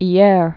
(ē-âr, yâr)